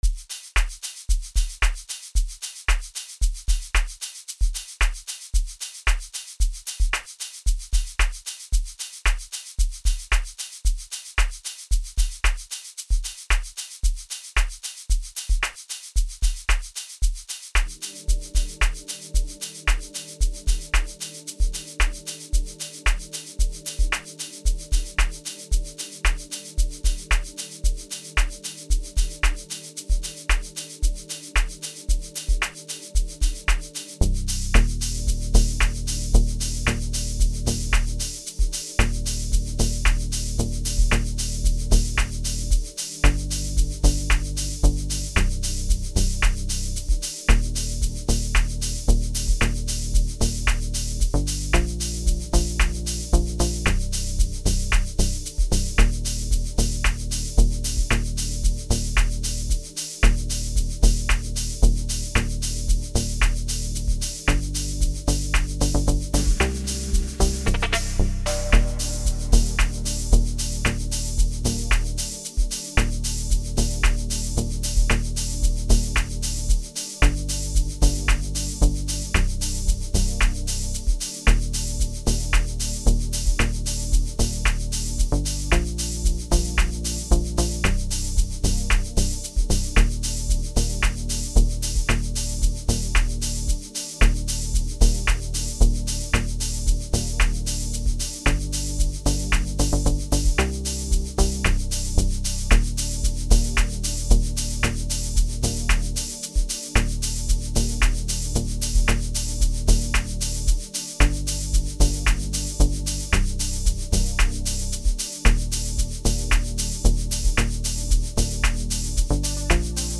05:48 Genre : Amapiano Size